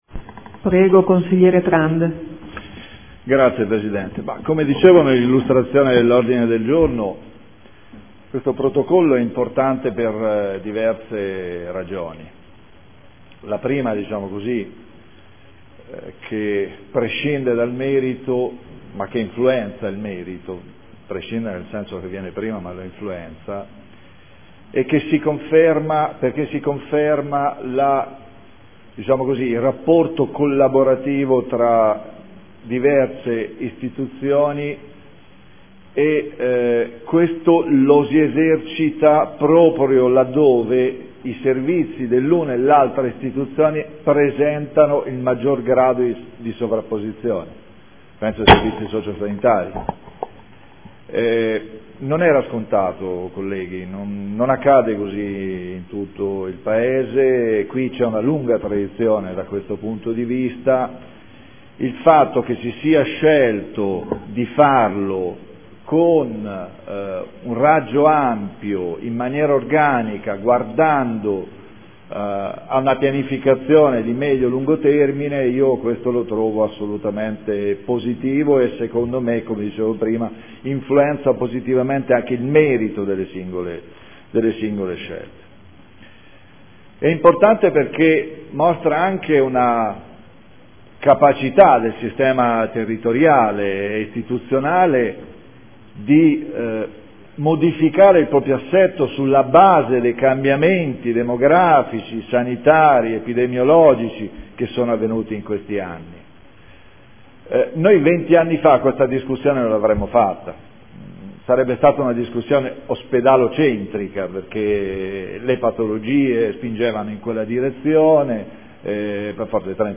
Seduta del 3/11/2014. Dibattito su ordini del giorno